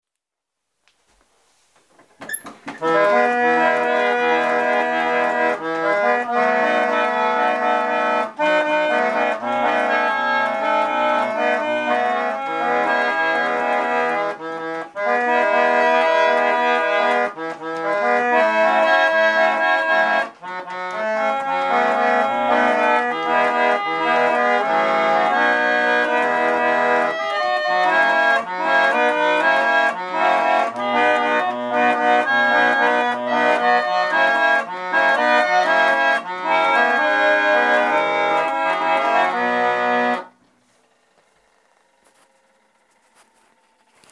So what does it sound like?
The rich full sound is produced as air is moved over the brass reed, causing it to vibrate.
Interestingly, as the pallet lever slides from pin to pin during a held note, the pallet wavers, producing a vibrato effect.